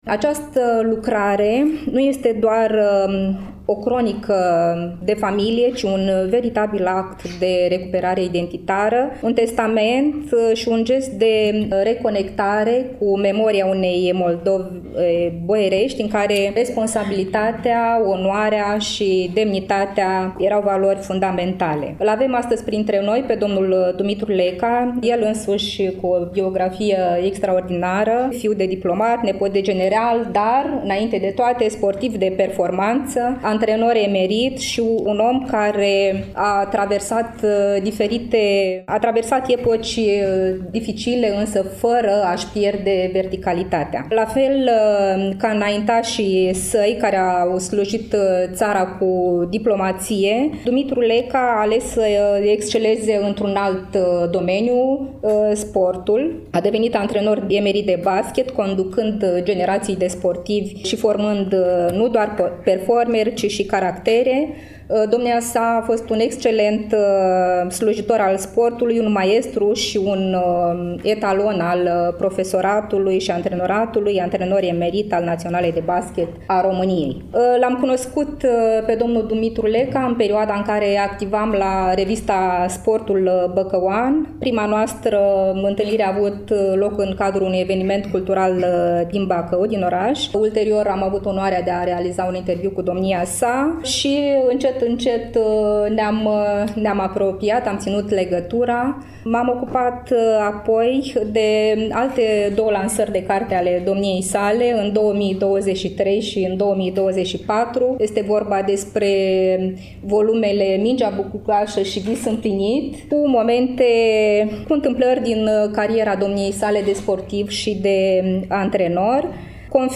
Volumul a fost lansat, la Iași, nu demult, în incinta Muzeului Memorial „Mihail Kogălniceanu” din cadrul Complexului Muzeal Național „Moldova”.